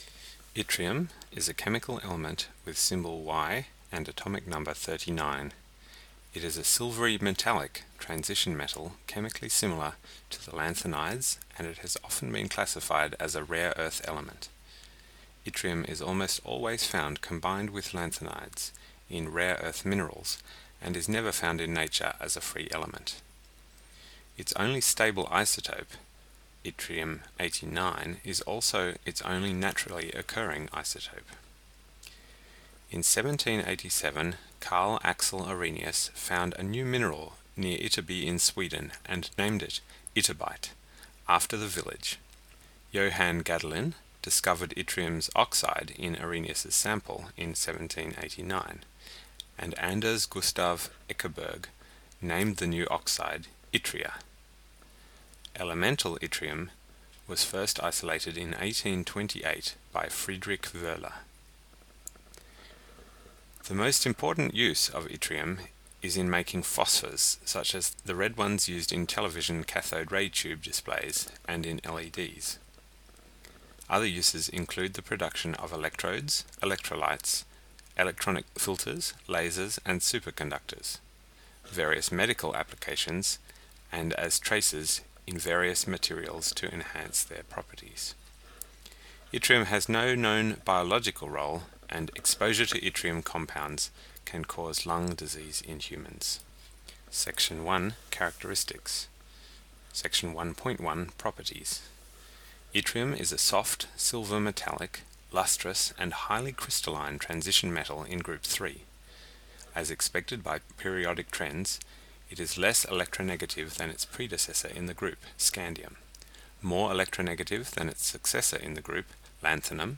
ملف تاريخ الملف وصلات معلومات الصورة (ميتا) Yttrium_spoken.ogg  (Ogg Vorbis ملف صوت، الطول 29ق 1ث، 96كيلوبيت لكل ثانية) وصف قصير ⧼wm-license-information-description⧽ Yttrium spoken.ogg English: This is a spoken version of w:Yttrium version [1] .
Yttrium_spoken.ogg.mp3